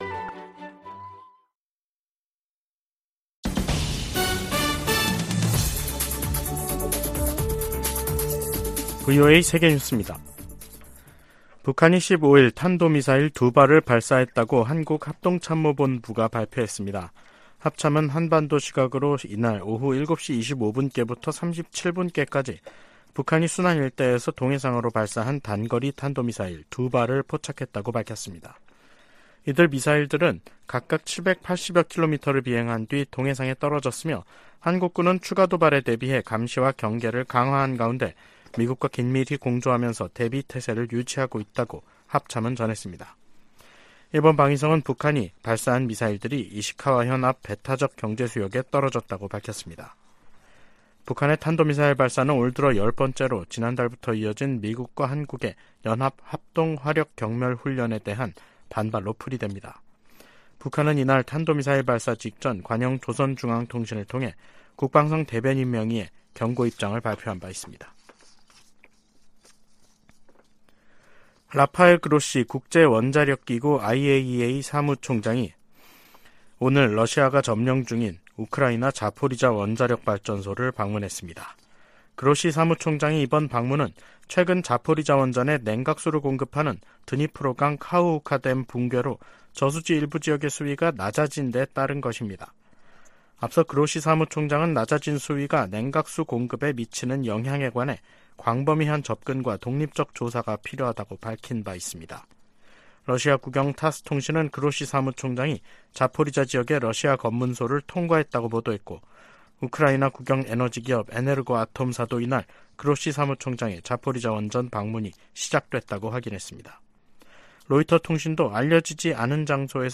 VOA 한국어 간판 뉴스 프로그램 '뉴스 투데이', 2023년 6월 15일 3부 방송입니다. 미국은 심해지는 중국과의 경쟁을 관리하기 위해 한국, 일본 등과 동맹을 강화하고 있다고 미국 정부 고위 관리들이 밝혔습니다. 미국 하원 세출위원회 국방 소위원회는 2024회계연도 예산안에서 미군 유해 수습과 신원 확인 외에는 어떤 대북 관련 지원도 할수 없도록 했습니다.